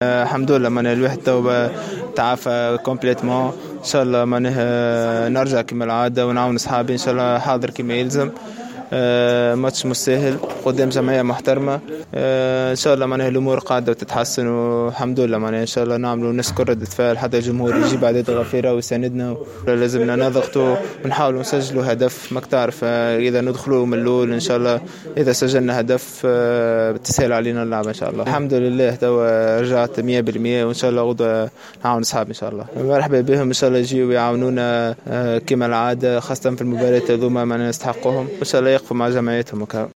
خلال الندوة الصحفية التي عقدها النجم الساحلي بمناسبة اللقاء المرتقب ضد الهلال...